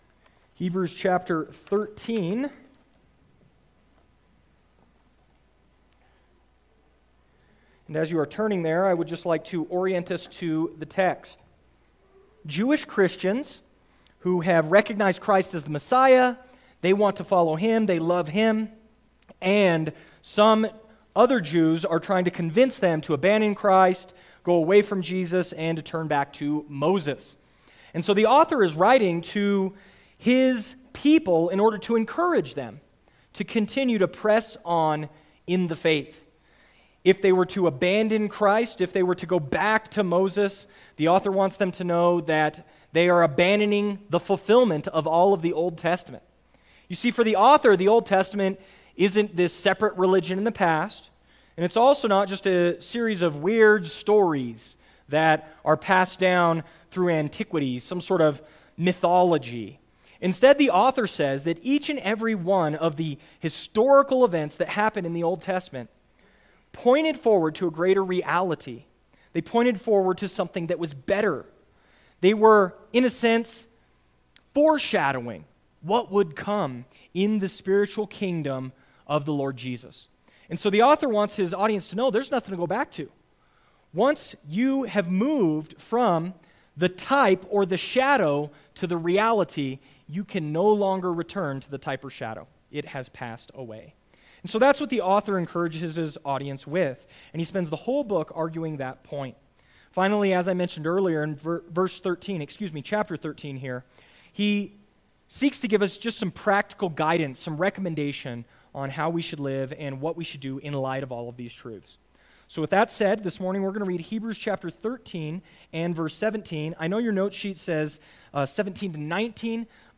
Sermons | First Baptist Church of Leadville